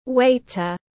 Bồi bàn nam tiếng anh gọi là waiter, phiên âm tiếng anh đọc là /ˈweɪtər/.